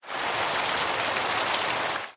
Minecraft Version Minecraft Version 25w18a Latest Release | Latest Snapshot 25w18a / assets / minecraft / sounds / ambient / weather / rain4.ogg Compare With Compare With Latest Release | Latest Snapshot
rain4.ogg